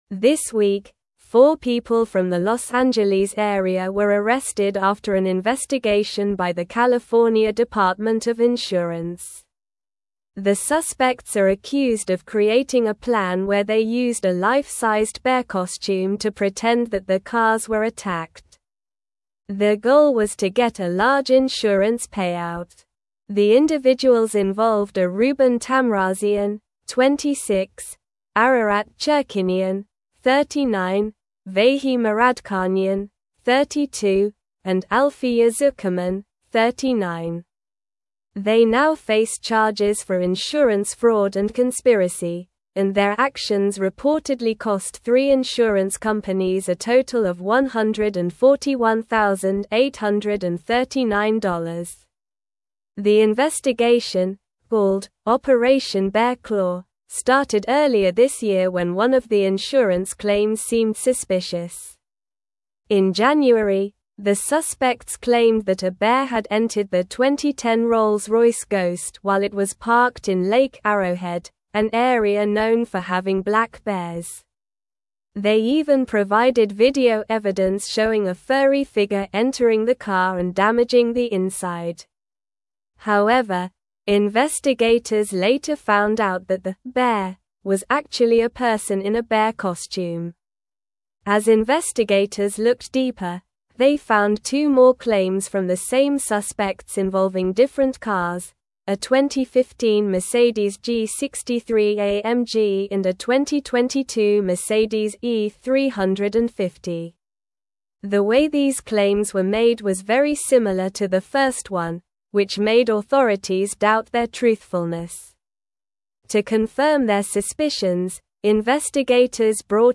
Slow
English-Newsroom-Upper-Intermediate-SLOW-Reading-Suspects-Arrested-for-Staging-Bear-Attack-Insurance-Fraud.mp3